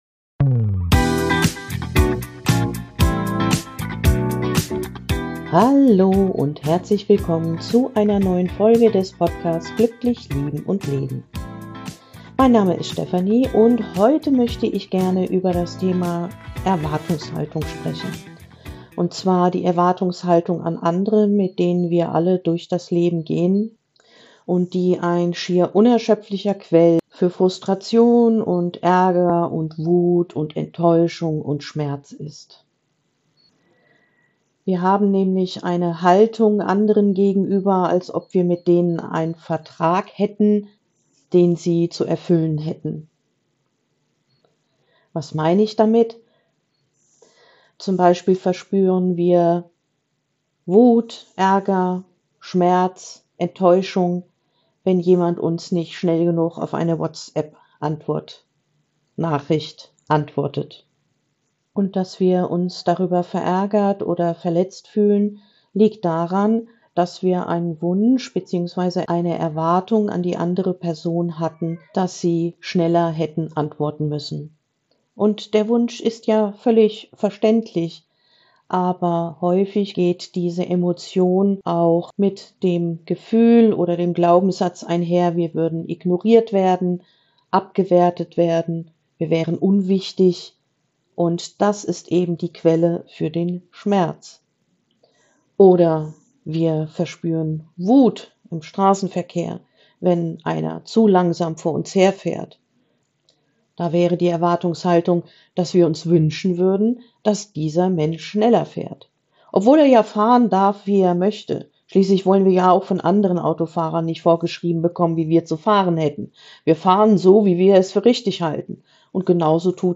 Wie unsere unbewussten Erwartungen an andere Frust, Wut und Enttäuschung in uns erzeugen - und wie wir durch Bewusstheit, Kommunikation und innere Verantwortung wieder zu Ruhe und Stärke finden. Inklusive einer geführten Entspannung zur Integration.